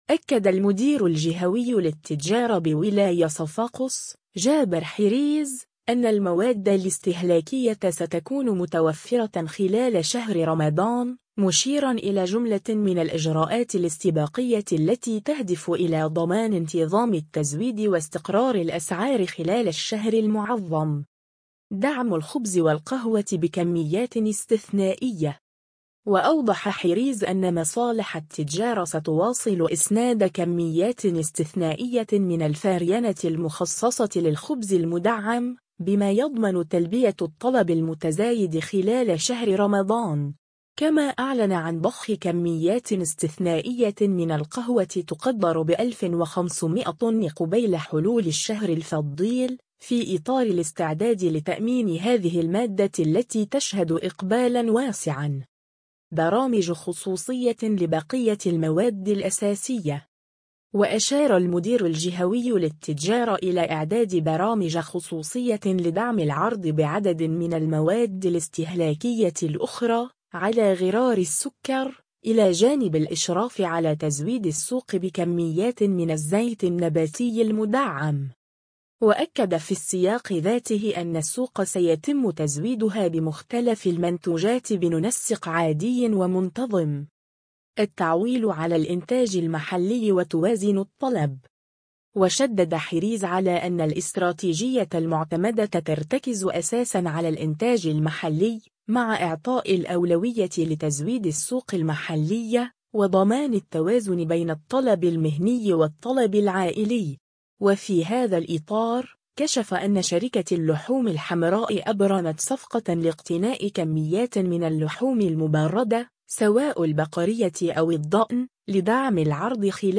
وفي ختام تصريحه، أشار المدير الجهوي للتجارة إلى أنّه تم، خلال جلسة عمل انتظمت اليوم الجمعة بمقر ولاية صفاقس، استعراض مختلف الاستعدادات الخاصة بشهر رمضان، والتأكيد على الجاهزية الكاملة لمصالح التجارة لضمان حسن سير التزويد ومراقبة الأسعار.